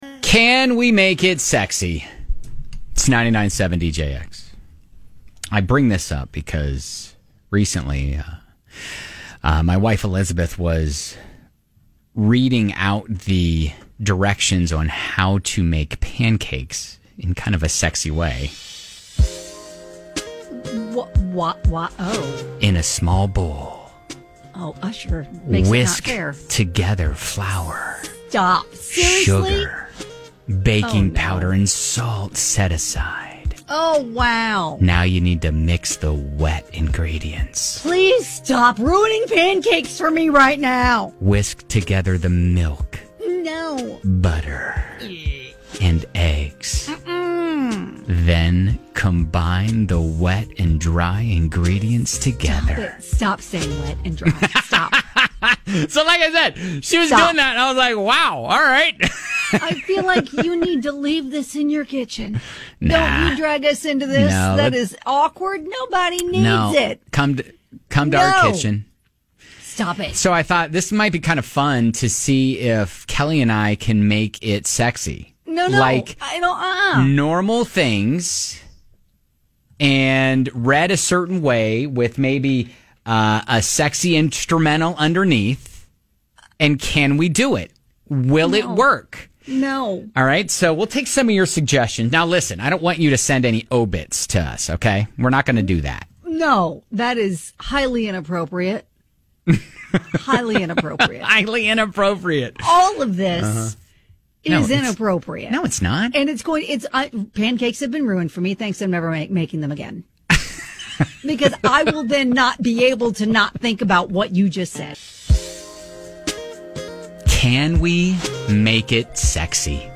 Can we take normal things and read them sexy?